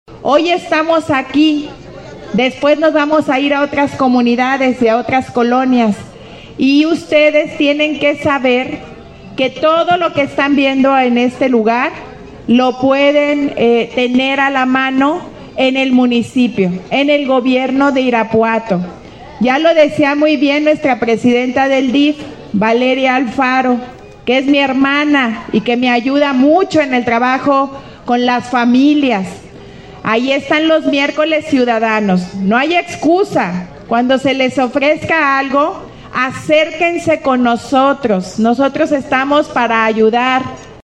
Lorena Alfaro, presidenta municipal